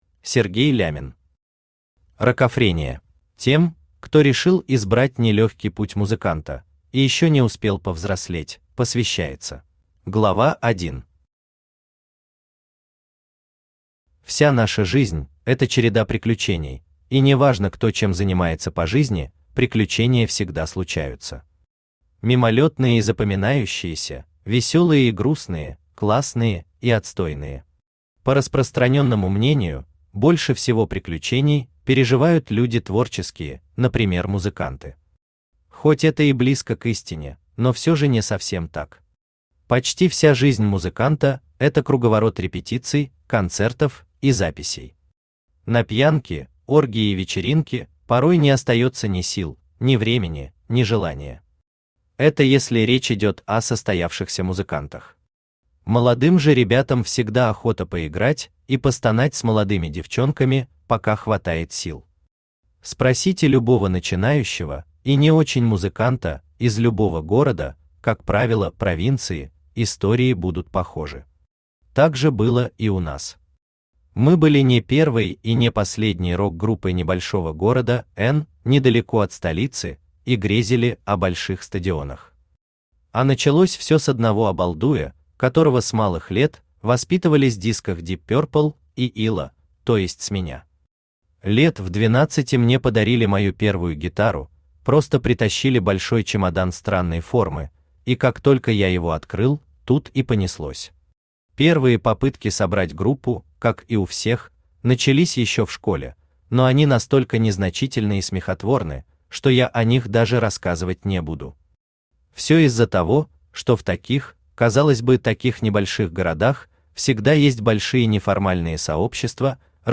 Aудиокнига Рокофрения Автор Сергей Лямин Читает аудиокнигу Авточтец ЛитРес. Прослушать и бесплатно скачать фрагмент аудиокниги